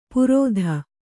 ♪ purōdha